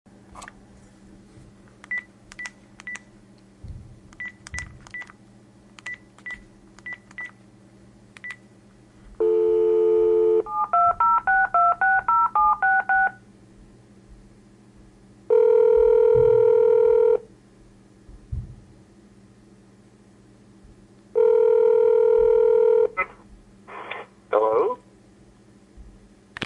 Download Phone sound effect for free.
Phone